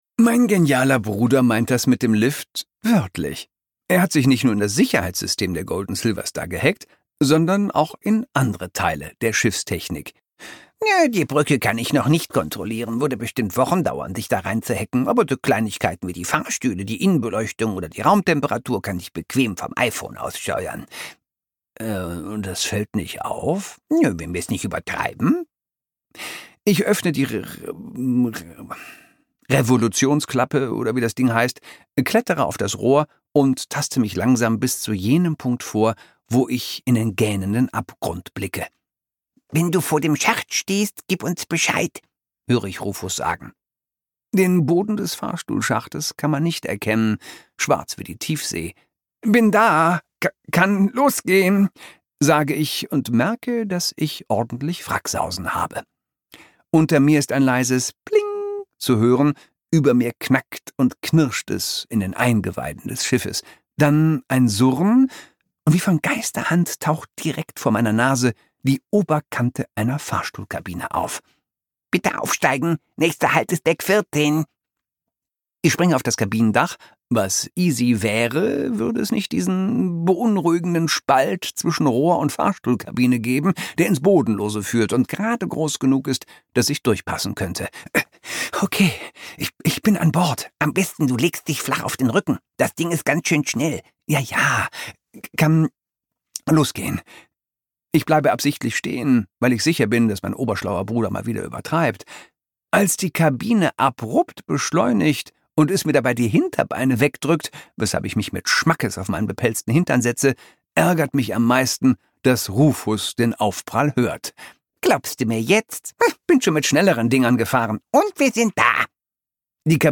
Schiffe versenken Ein Erdmännchen-Krimi | Zwei Erdmännchen-Spürnasen auf Kreuzfahrt Moritz Matthies (Autor) Christoph Maria Herbst (Sprecher) Audio Disc 2024 | 4.